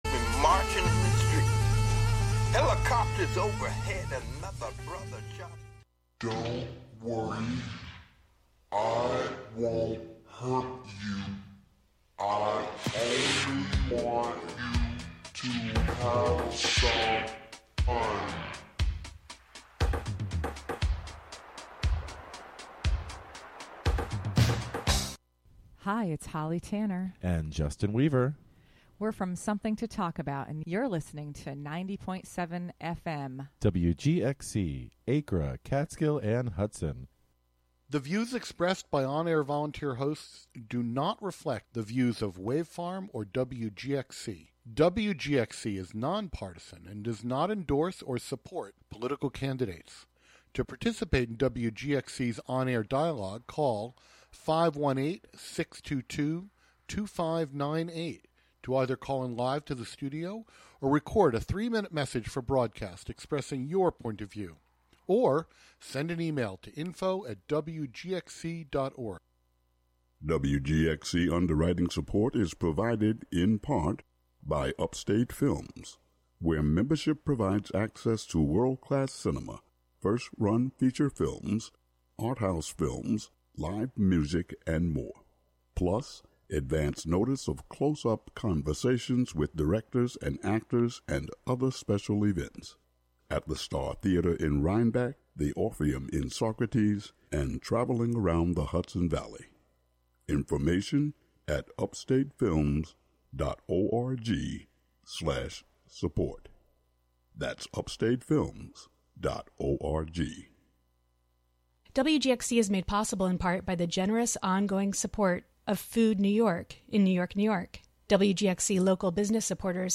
Show includes local WGXC news at beginning and midway through.